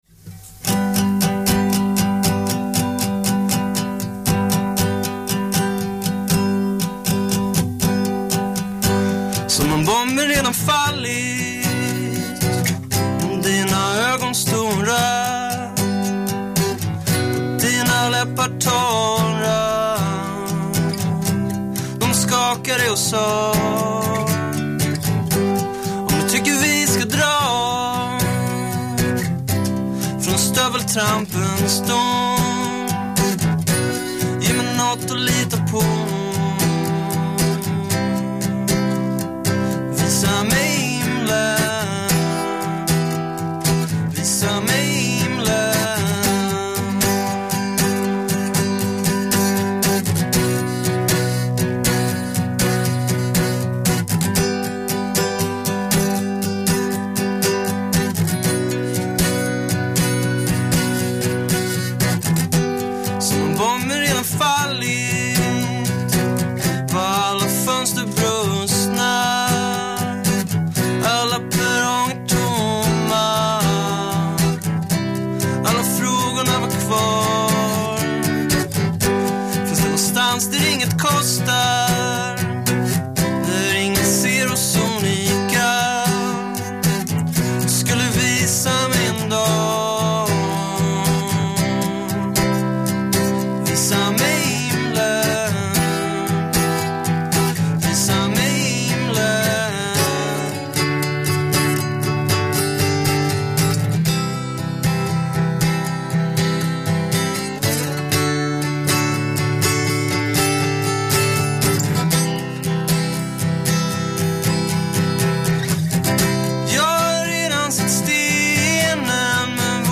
live unplugged